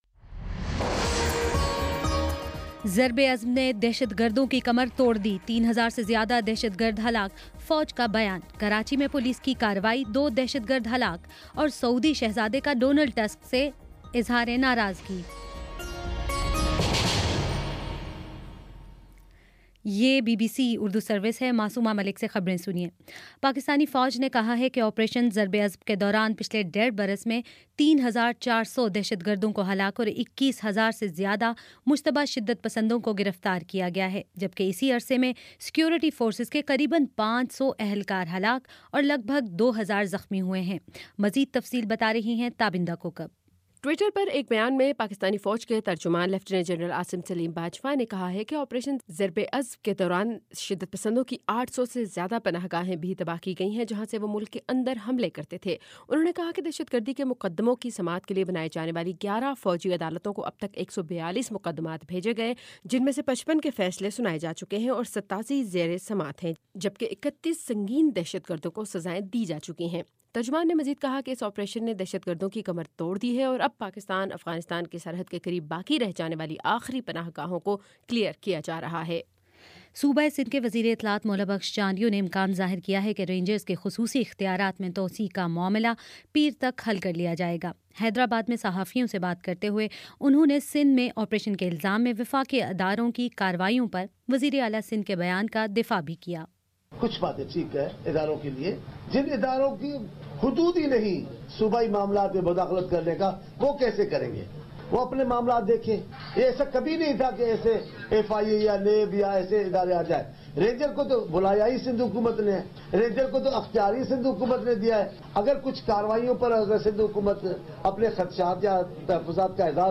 دسمبر 12 : شام پانچ بجے کا نیوز بُلیٹن